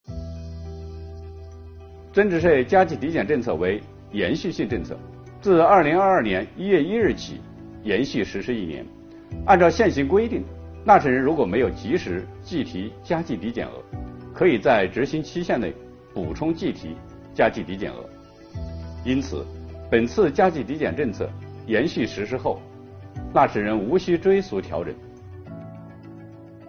本期课程由国家税务总局货物和劳务税司副司长刘运毛担任主讲人，对2022年服务业领域困难行业纾困发展有关增值税政策进行详细讲解，方便广大纳税人更好地理解和享受政策。